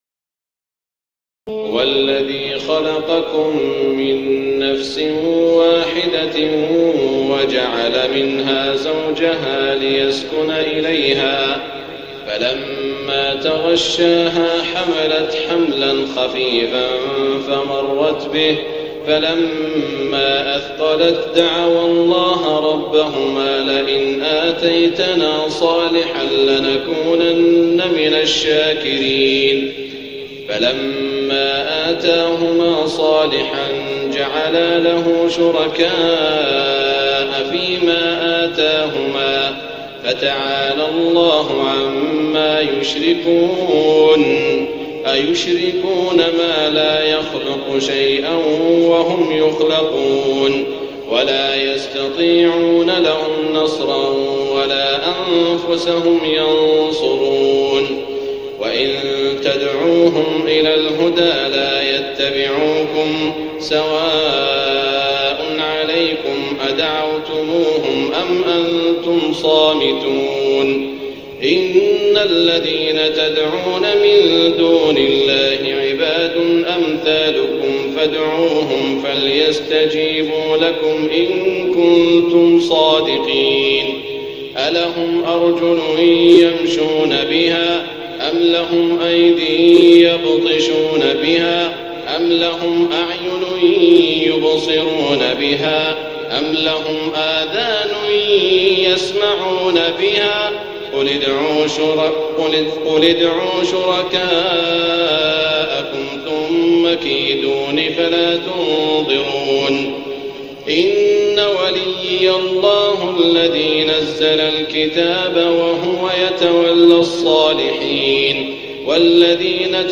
تهجد ليلة 29 رمضان 1423هـ من سورتي الأعراف (189-206) و الأنفال (1-40) Tahajjud 29 st night Ramadan 1423H from Surah Al-A’raf and Al-Anfal > تراويح الحرم المكي عام 1423 🕋 > التراويح - تلاوات الحرمين